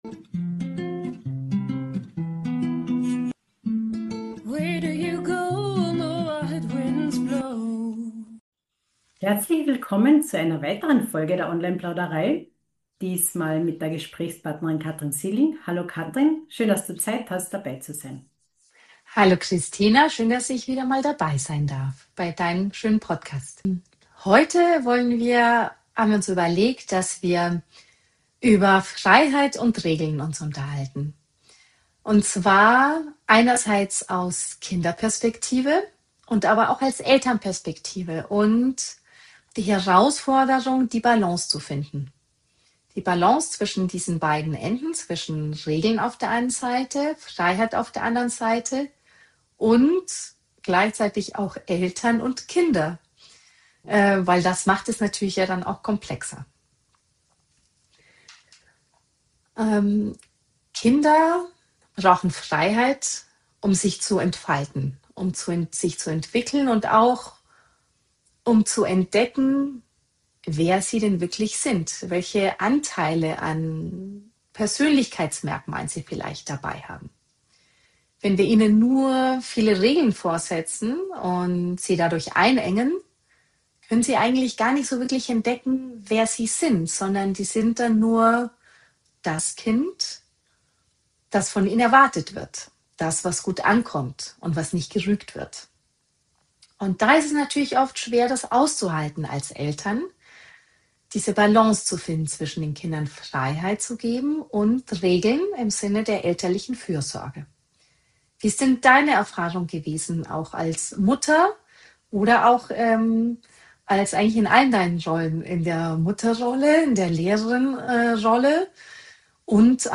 Ein ehrliches, warmes Gespräch über Erziehung, innere Konflikte, alte Muster und neue Wege.